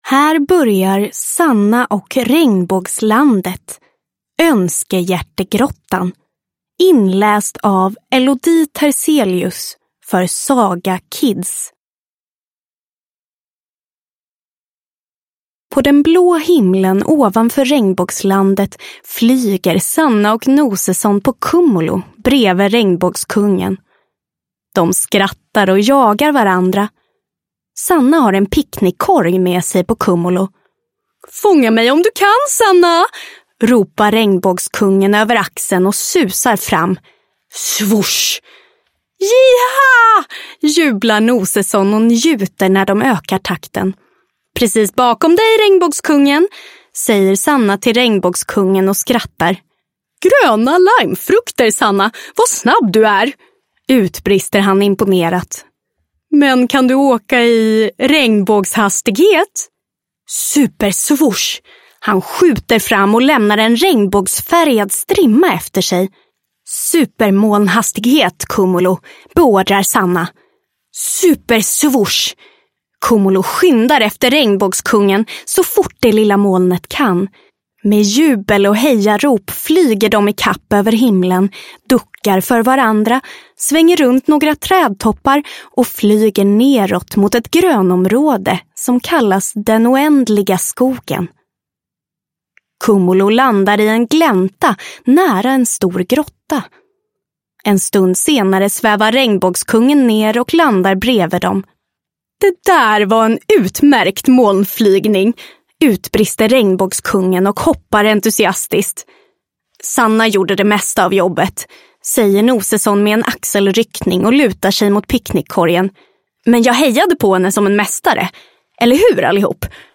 Sanna och Regnbågslandet – Önskehjärtegrottan – Ljudbok